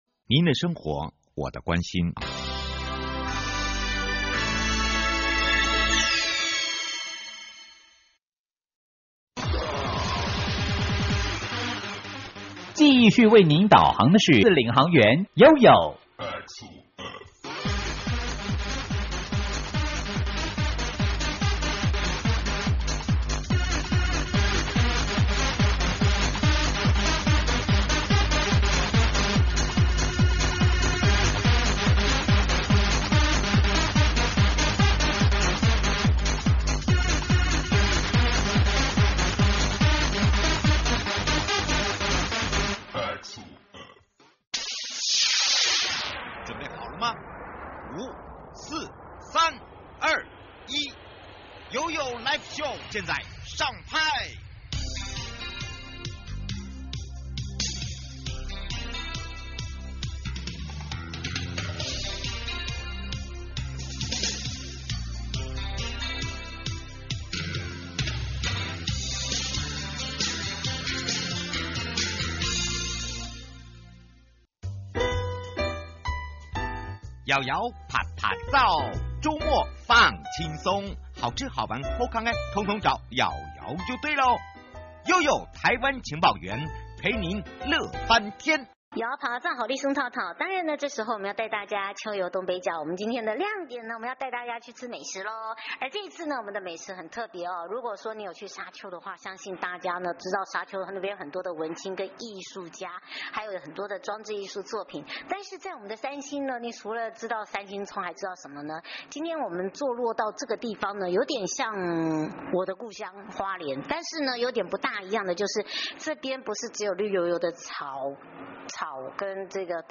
受訪者： 1.東北角管理處